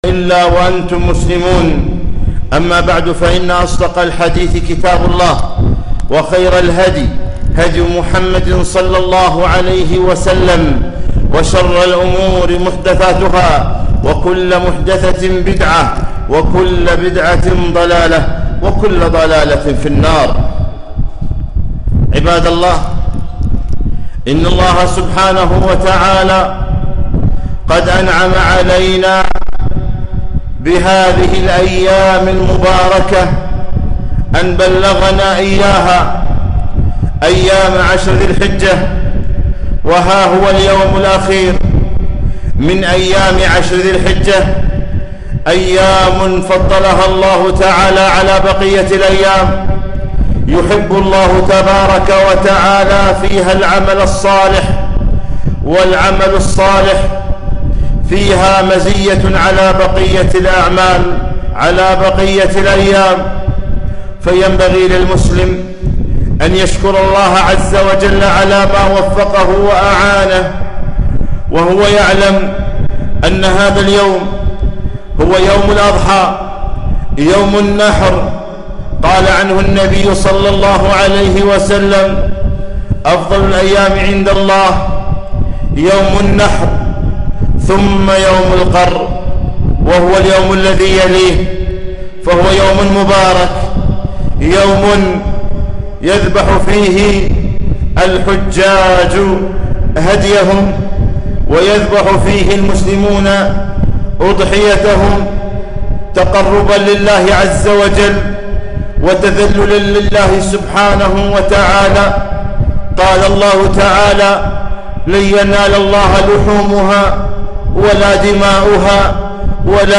خطبة عيد الأضحى في مسجد عطارد بن حاجب لعام 1442هـ